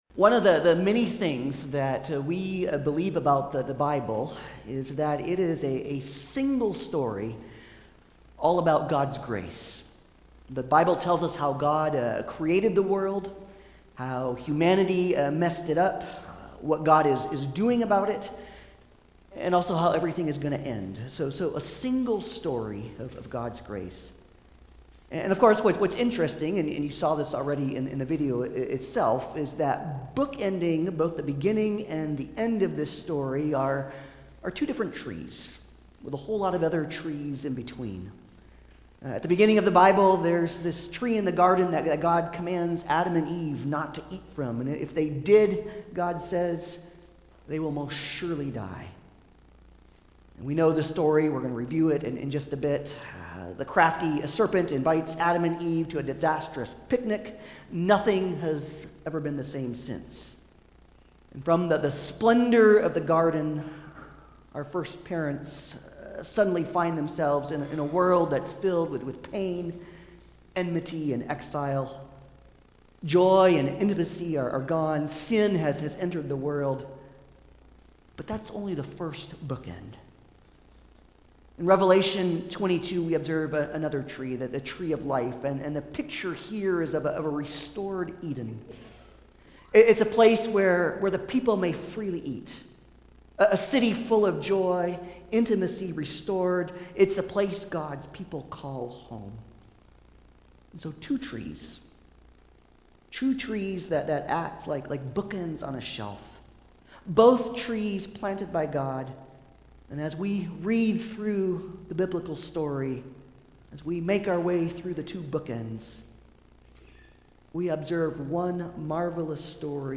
Passage: Genesis 2:8-9, 15-17, 3:1-19 Service Type: Sunday Service